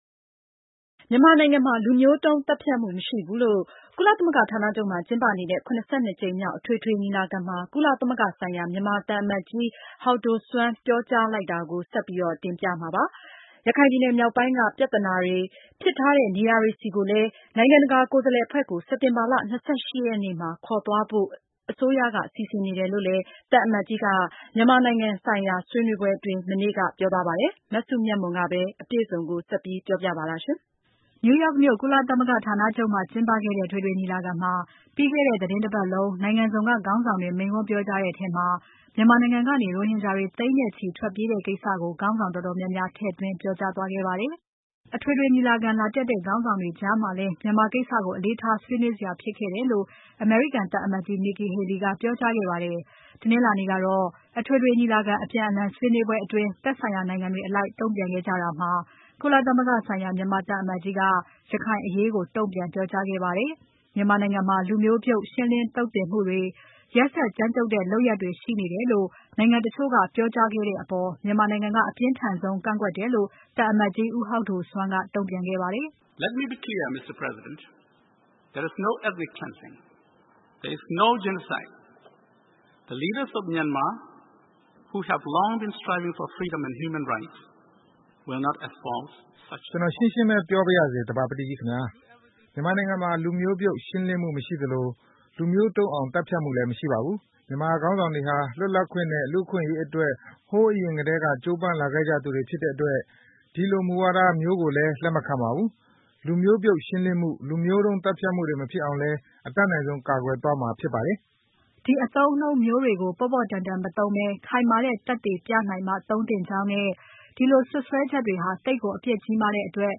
၇၂ ကြိမ်မြောက် အထွေထွေညီလာခံမှာ ကုလသမဂ္ဂဆိုင်ရာ မြန်မာသံအမတ်ကြီး ဦးဟောက်ဒိုဆွမ်းတုံ့ပြန်စဉ်